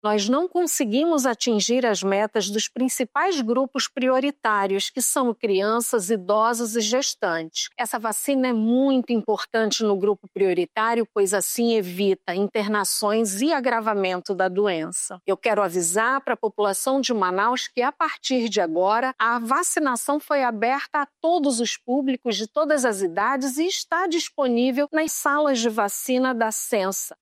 A secretária municipal de saúde de Manaus, Shádia Fraxe, explica que a campanha não atingiu a meta prevista e faz um alerta à população.
SONORA-1-AMPLIACAO-VACINA-INFLUENZA-.mp3